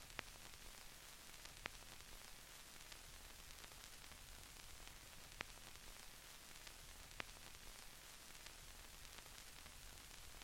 Tag: 92 bpm Hip Hop Loops Fx Loops 3.82 MB wav Key : Unknown